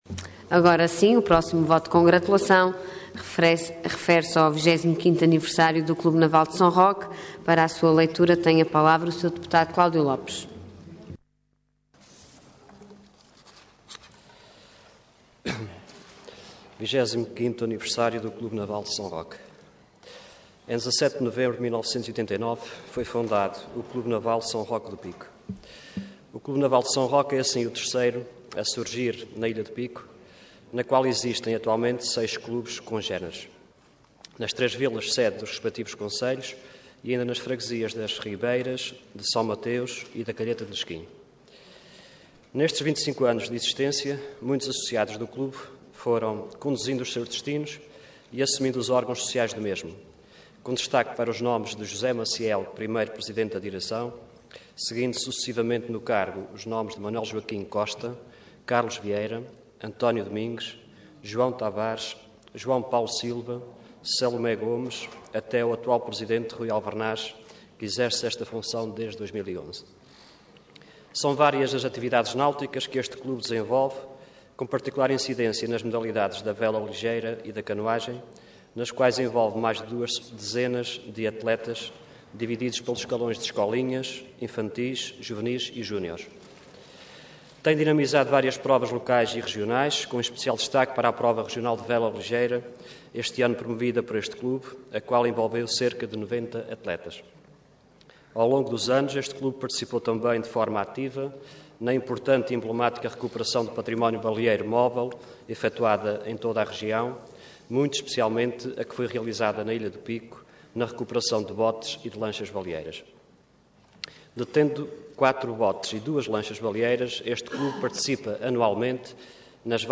Detalhe de vídeo 11 de dezembro de 2014 Download áudio Download vídeo Processo X Legislatura 25.º Aniversário do Clube Naval de São Roque Intervenção Voto de Congratulação Orador Cláudio Lopes Cargo Deputado Entidade PSD